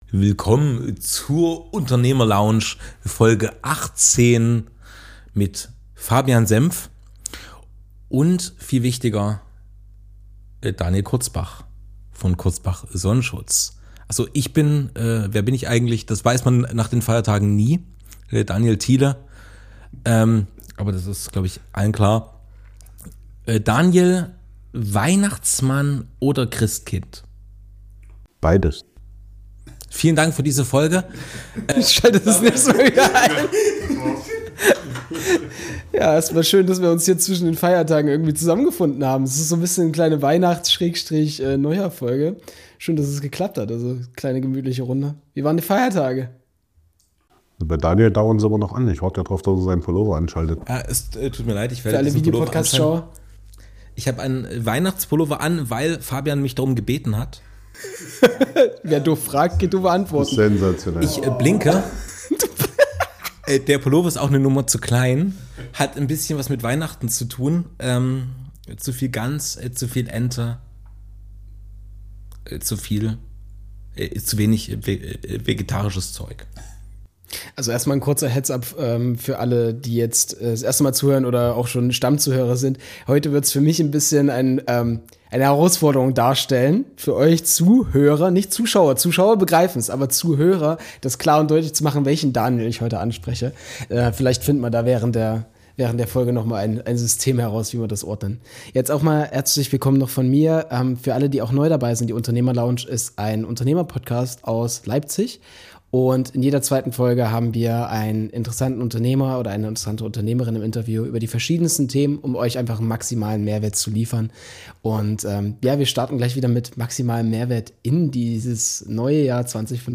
Der Podcast bietet umfassende Informationen und humorvolle Gespräche, die sowohl unterhaltsam als auch informativ sind.